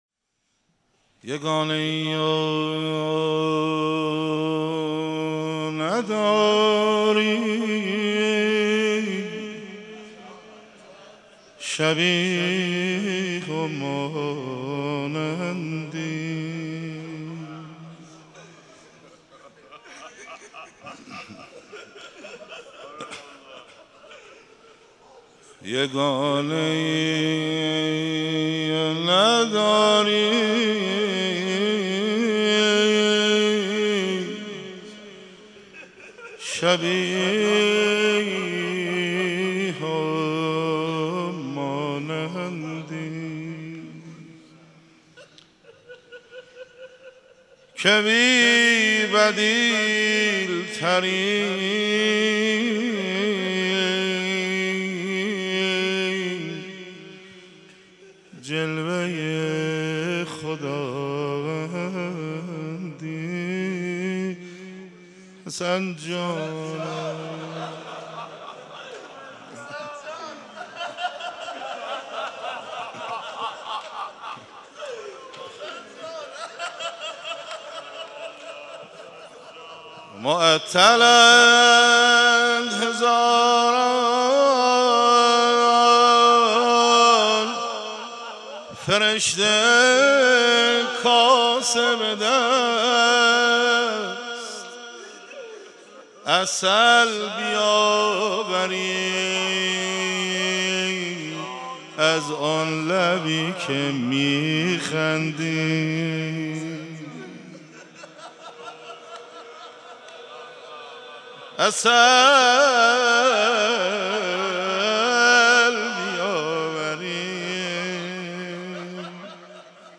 روضه و مناجات
مداحی(1).mp3